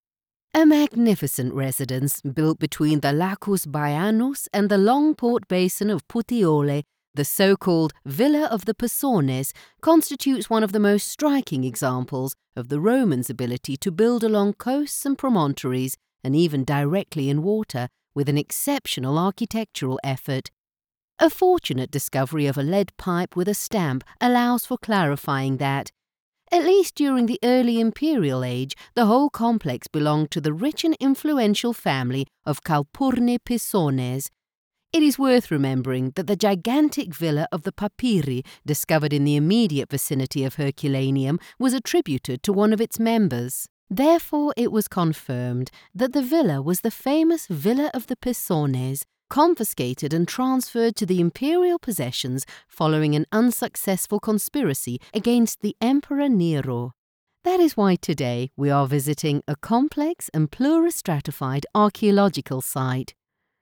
Audioguides
Mon accent anglais neutre est particulièrement adapté aux marchés internationaux.
Ma voix est naturelle et chaleureuse, tout en étant résonnante et autoritaire.
Micro Audio Technica AT2020